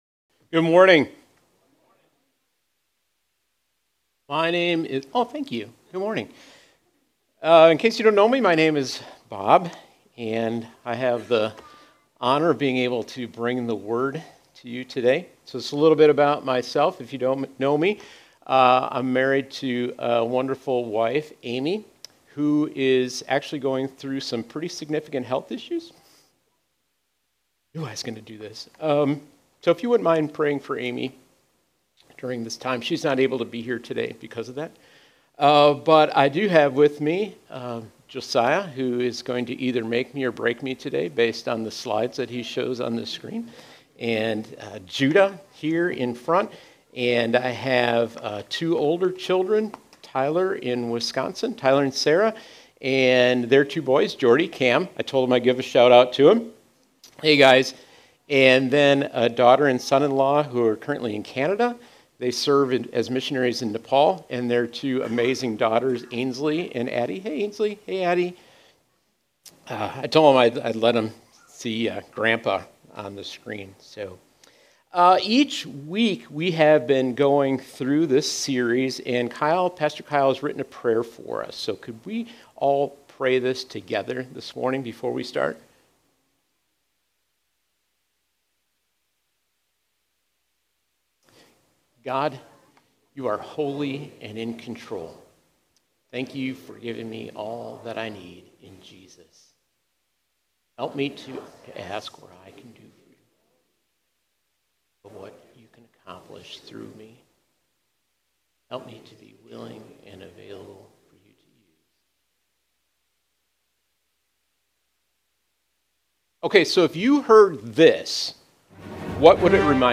Grace Community Church Dover Campus Sermons 7_20 Dover Campus Jul 21 2025 | 00:23:32 1x 00:00 / 00:23:32 Subscribe Share RSS Feed Share Link Embed